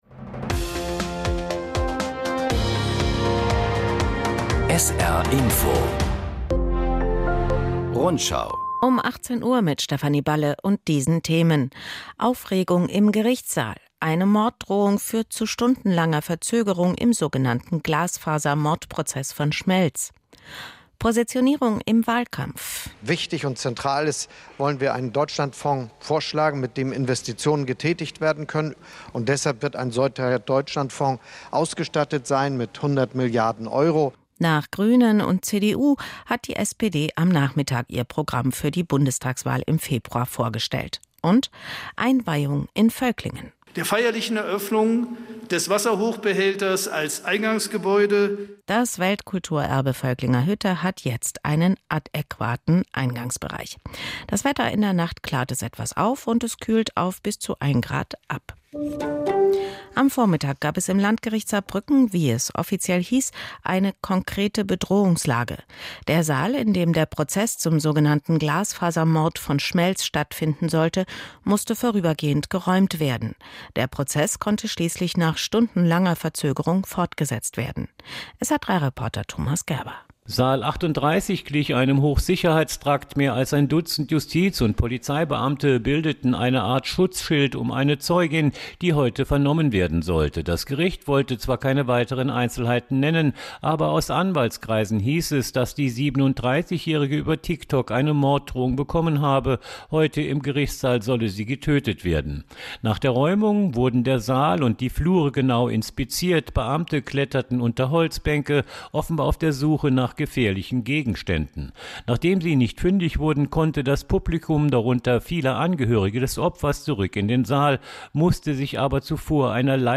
… continue reading 4 Episoden # Nachrichten